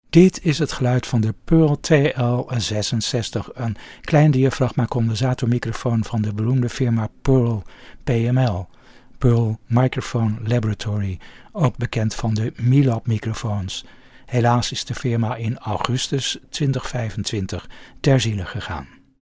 Bij de wat bescheidener modellen hoort de moderne klassieker van deze maand: de Pearl TL 66 kleinmembraan cardioïde 'potlood' condensator, ontworpen voor zowel studiogebruik als muzikanten.
Pearl gebruikte deze om een interessante microfoon te creëren met een helder en neutraal geluid en zeer weinig ruis.
Het geluid was niet vet, maar wel accuraat, dat viel bij sommigen in de smaak, maar niet bij iedereen.
Pearl TL66 sound NL.mp3